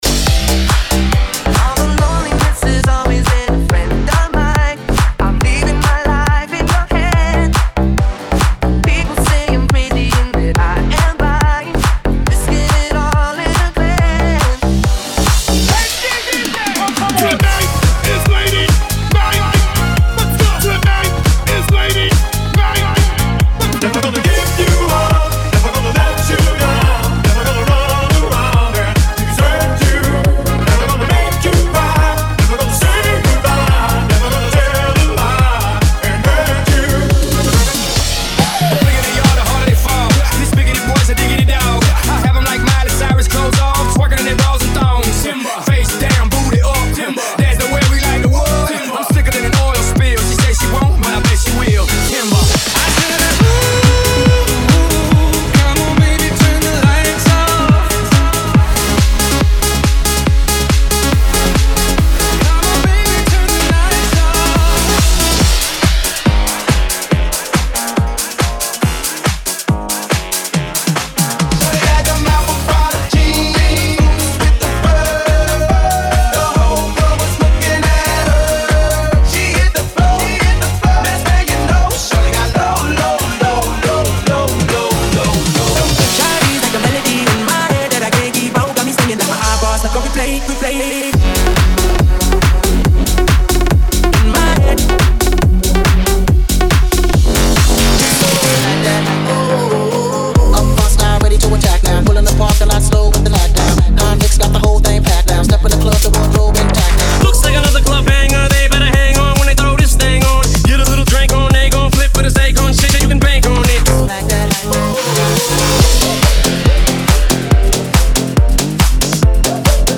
Smooth Club, House & Anthem Sounds.